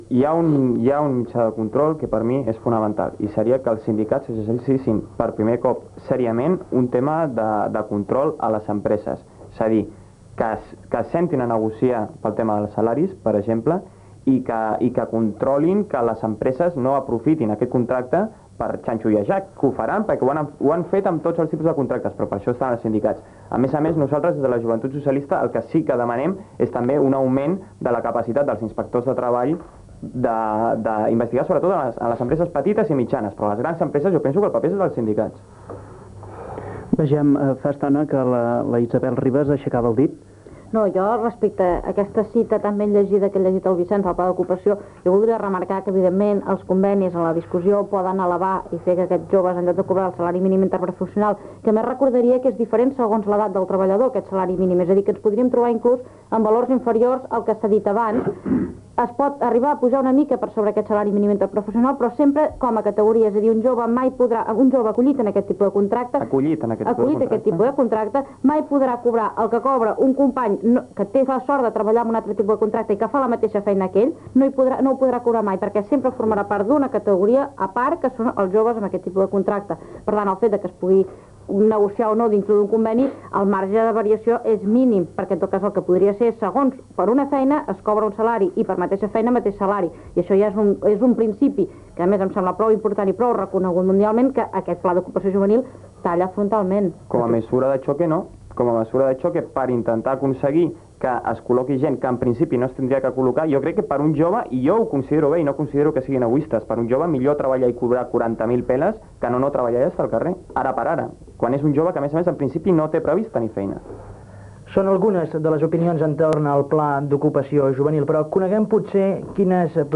Debat de joves sobre els salaris i el Pla d'ocupació jovenil
indicatiu del programa, publcitat, indicatiu, lectura del conte "Blanc" de Màrius Serra, comiat del programa